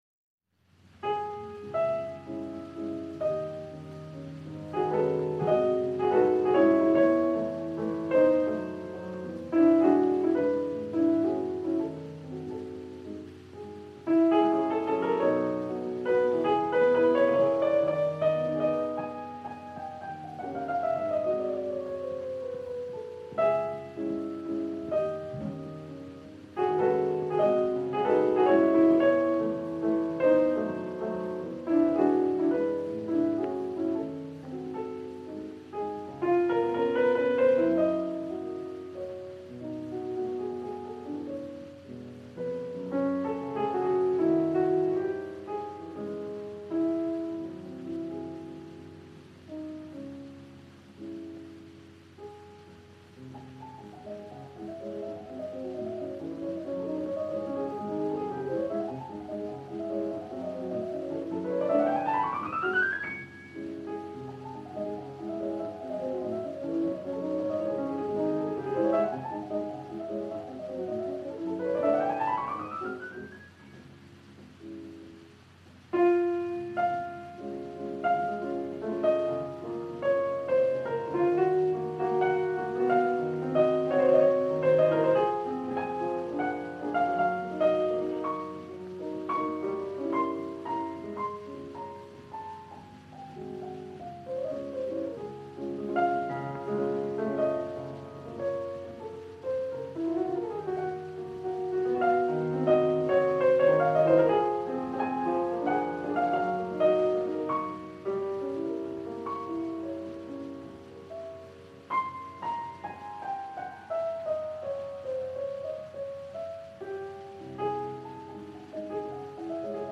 Прослушайте это музыкальное произведение в исполнении Рахманинова….
Frederik-SHopen-Ispolnyaet-Rakhmaninov-Vals-N7-c-moll-op.64-N-2.mp3